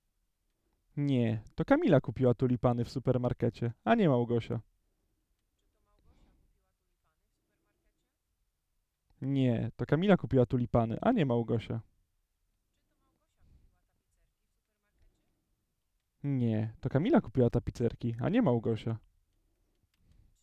Male: Answering
Mężczyzna: Odpowiadanie